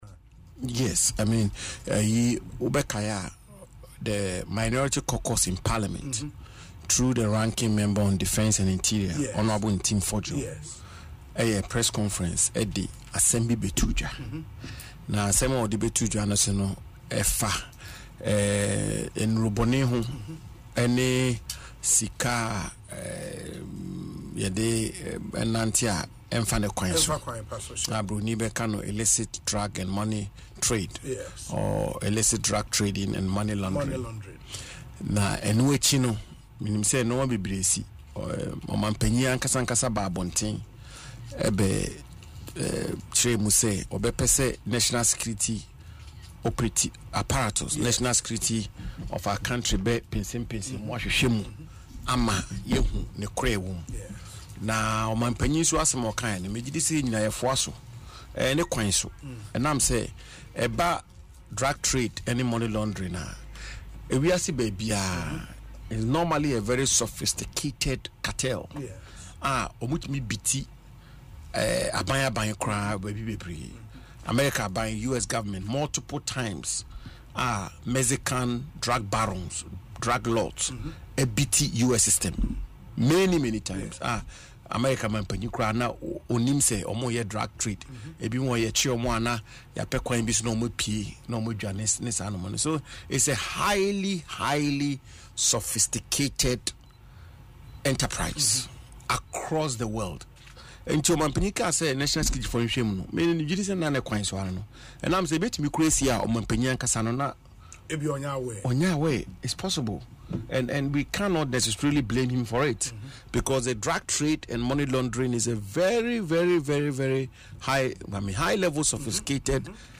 In an interview on Asempa FM Ekosii Sen, the Ranking Member on the Foreign Affairs Committee expressed support for the President’s actions, stating, “I believe all Ghanaians supported the directive because it was in the right direction. When it comes to the drug trade, it is a highly sophisticated cartel that can beat the government multiple times without any suspicion or knowledge.”